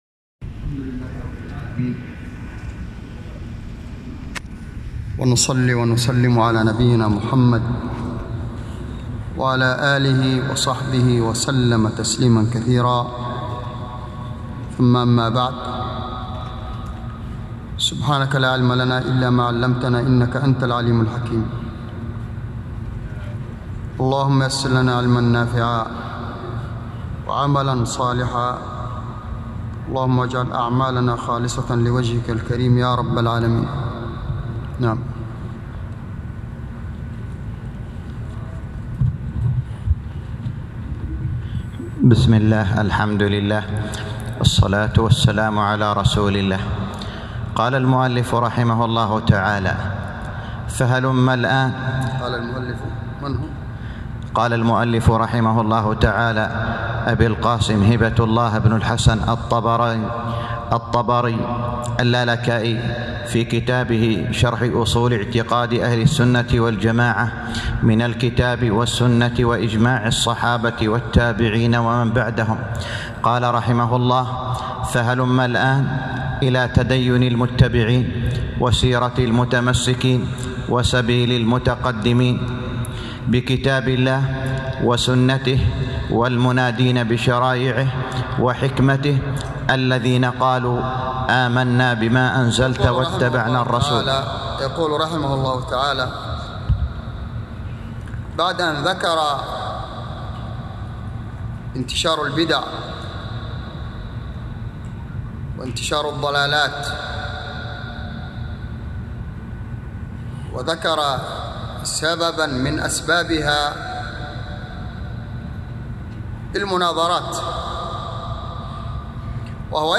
الدرس الخامس - شرح أصول اعتقاد اهل السنة والجماعة الامام الحافظ اللالكائي _ 5